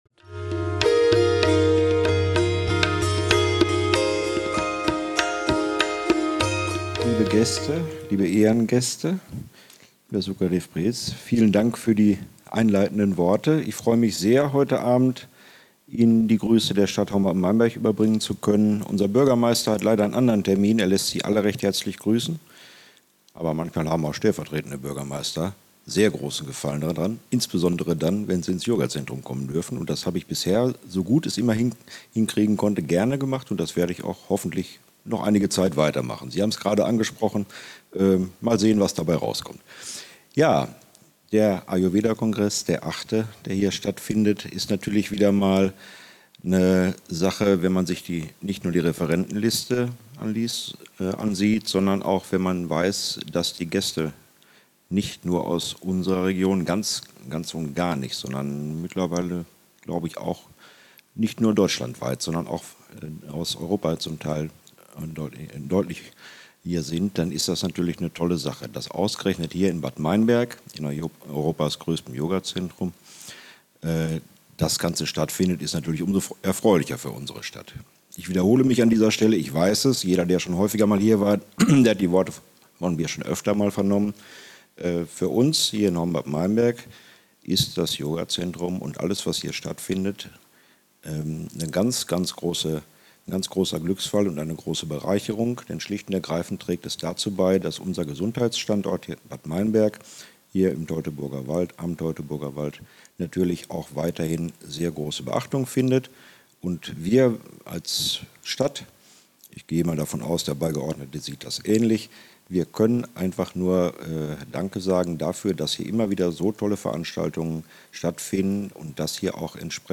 Grußworte von Frank Kuhlmann - Ayurveda Kongress 2014
Kuhlmann, stellvertretender Bürgermeister der Stadt Horn-Bad
Ayurveda-Kongress-Grussrede-stellvertretender-Buergermeister-Kuhlmann.mp3